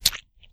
STEPS Pudle, Walk 29.wav